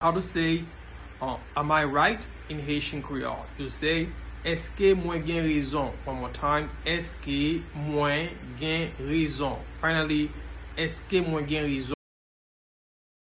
Am-I-right-in-Haitian-Creole-Eske-mwen-gen-rezon-pronunciation-by-a-Haitian-Creole-tutor.mp3